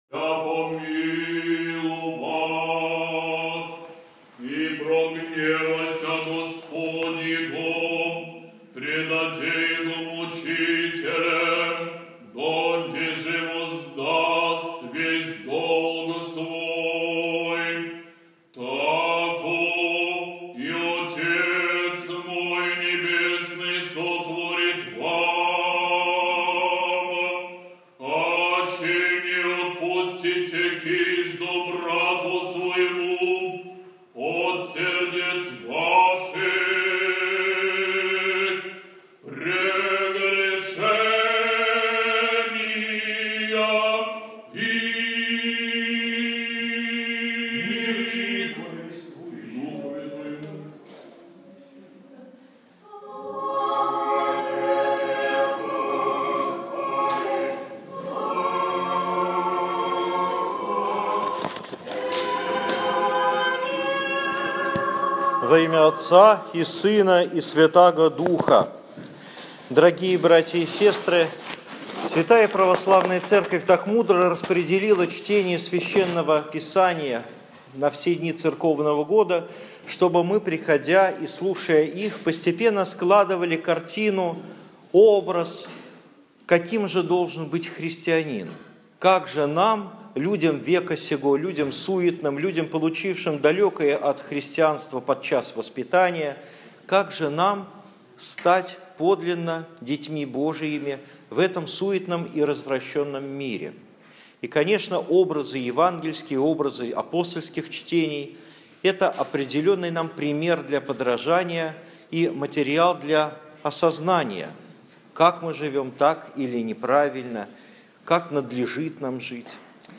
16 августа 2015 года. Неделя 11-я по Пятидесятнице. Проповедь на литургии в Петропавловском храме в Парголово